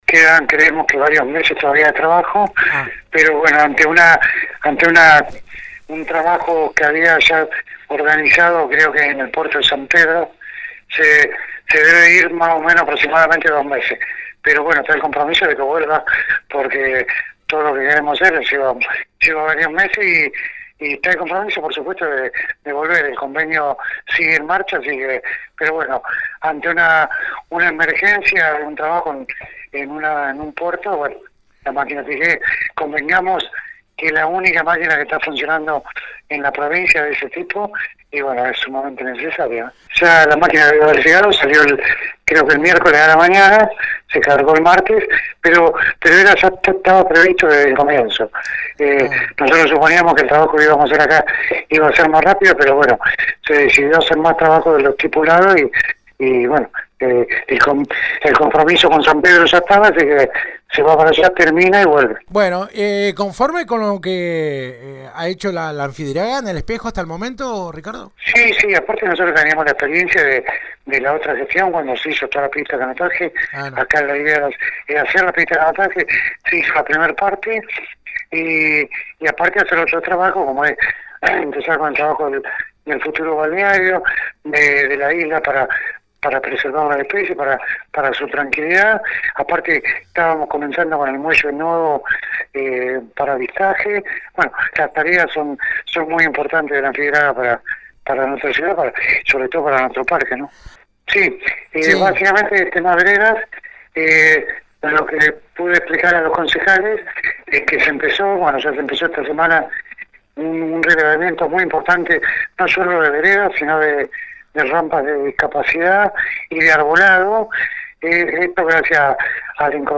«Ante un trabajo que ya se había organizado en el Puerto de San Pedro se debe ir aproximadamente dos meses. Pero regresará porque aún quedan varios meses de trabajo», explicó este fin de semana el secretario de Obras, Planeamiento y Servicios Públicos, Ricardo Lapadula, a FM Alpha. «Está el compromiso de volver a Las Flores para una segunda etapa de dragado y limpieza. El convenio sigue en marcha», remarcó luego.